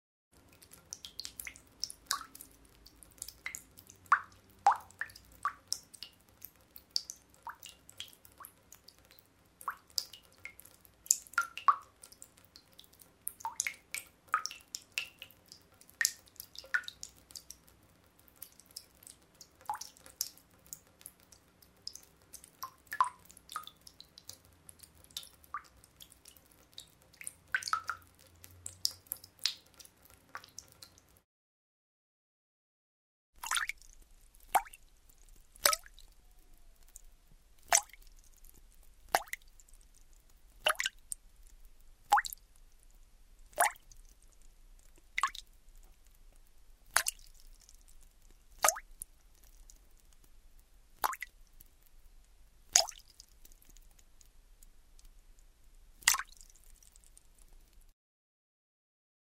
Звук капающей воды в наполненной ванне